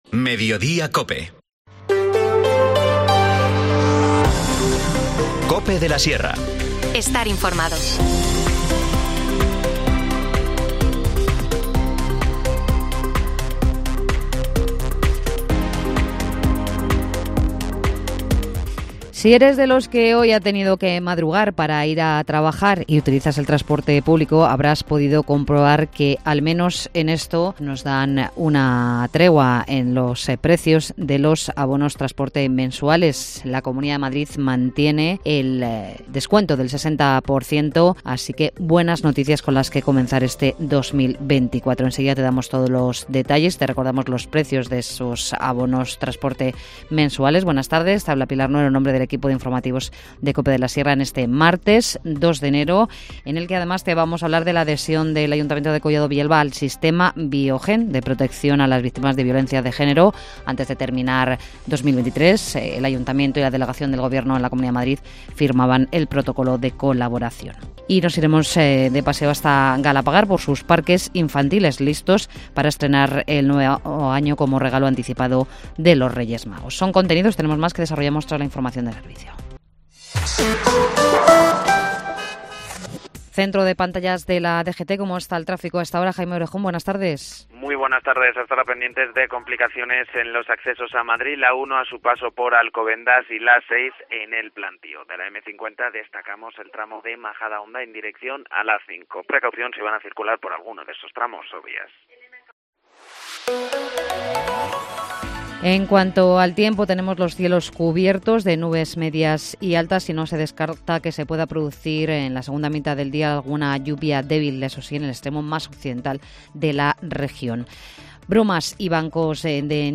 Informativo | Mediodía en Cope de la Sierra, 2 de enero de 2024